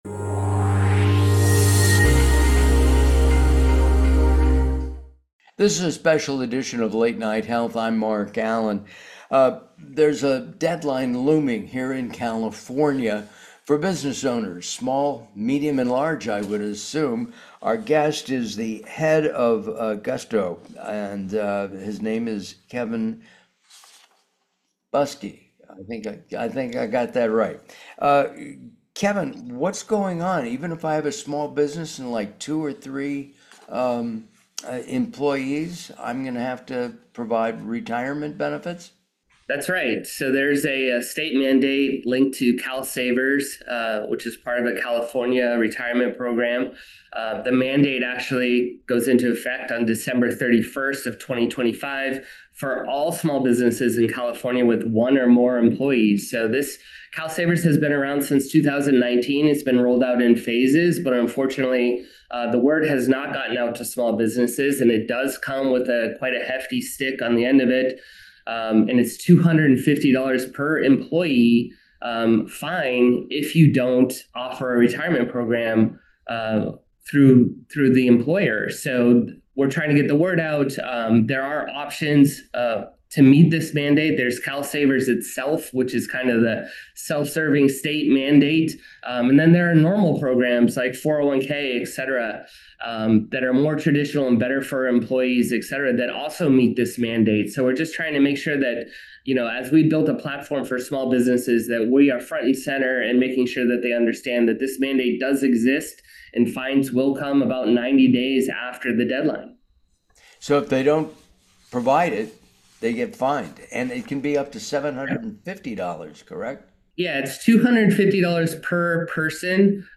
This interview is a must-listen for any California business owner who wants to avoid penalties, stay compliant, and turn a regulatory requirement into a competitive advantage.